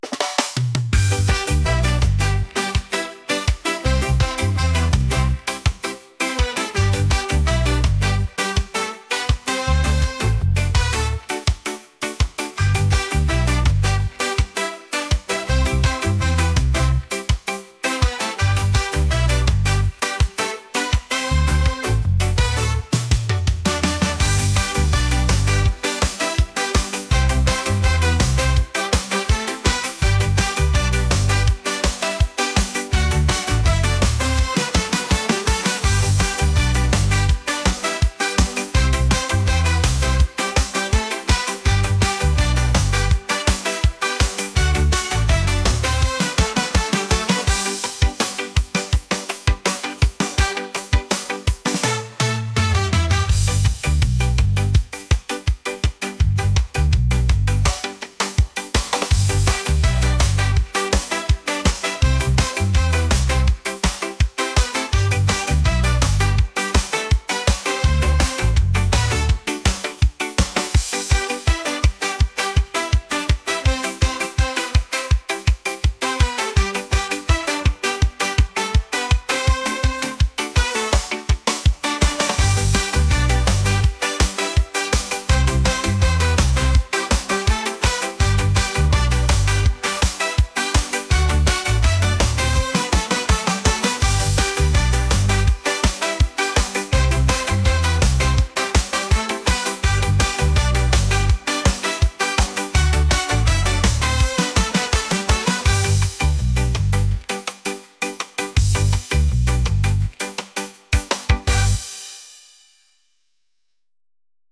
reggae | pop